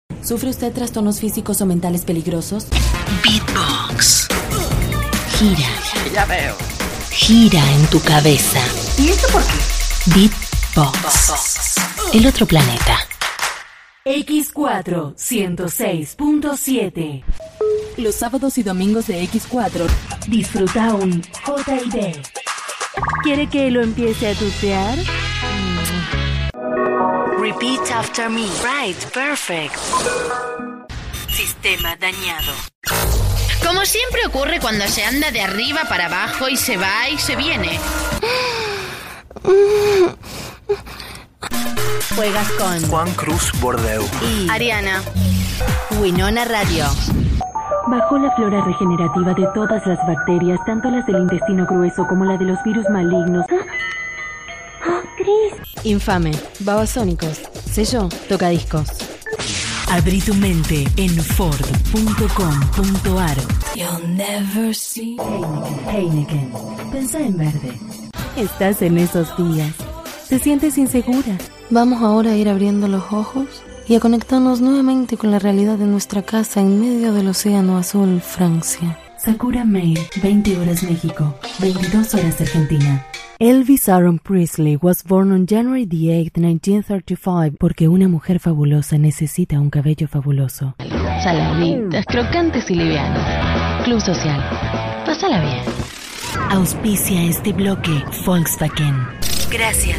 LOCUTORA. INTÉRPRETE. VOICEOVER.
Lo que no me resulta fácil es reunirlo para mostrarlo, así que me atrevo con esta simple muestra de voz, válida como galería de muestra.